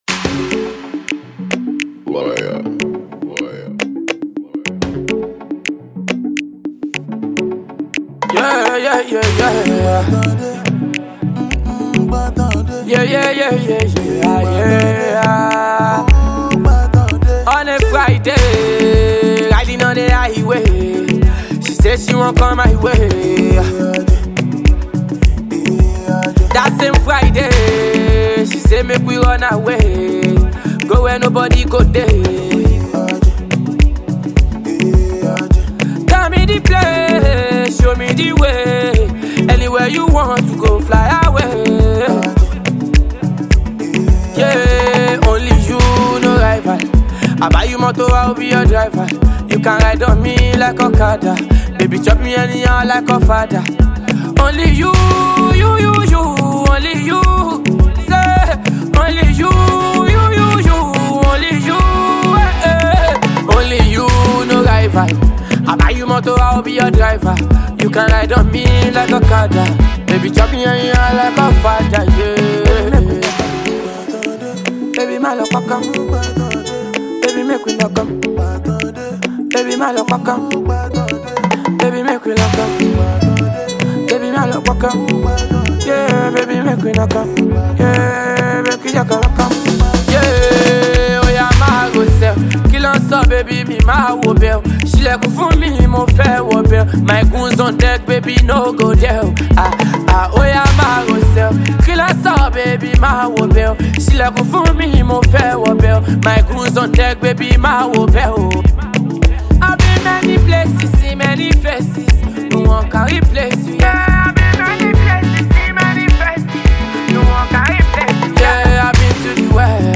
love theme single